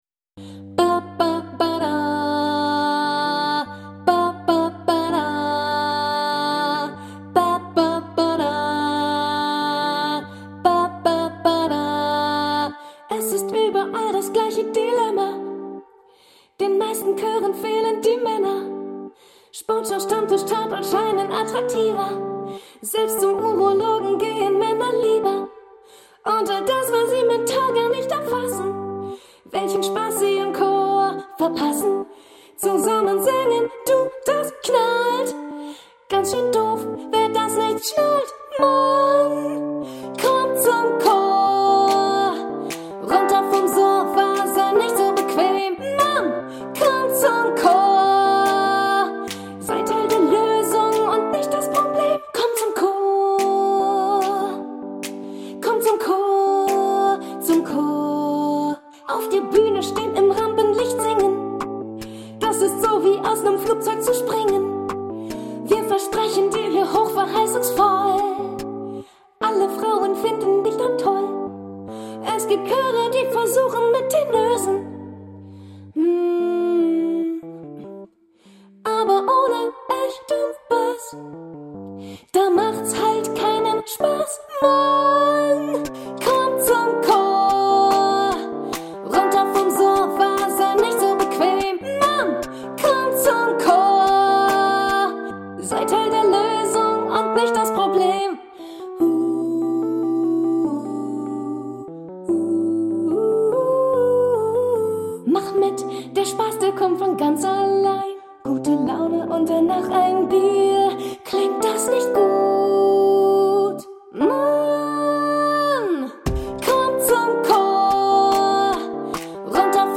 • Besetzung: 4-stimmig, a cappella
Sopranstimme